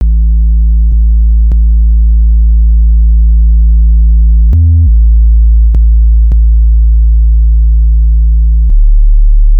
TSNRG2 Bassline 034.wav